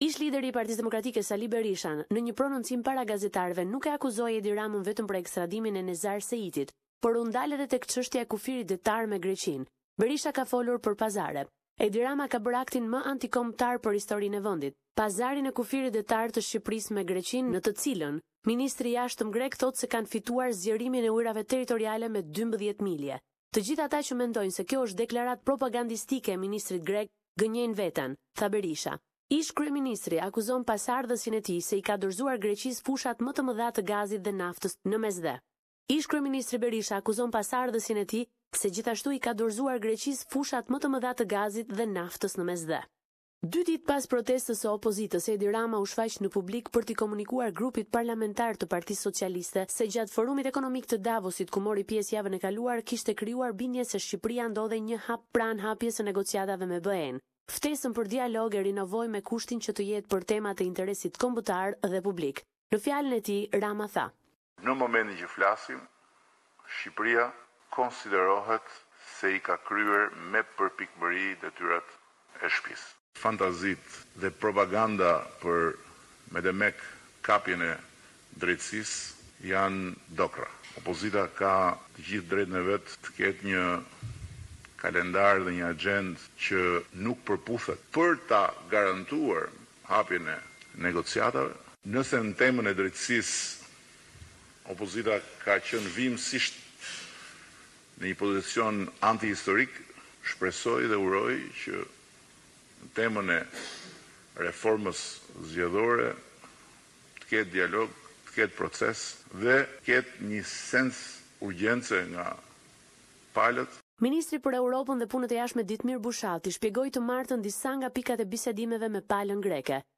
The weekly report with the latest developments in Albania.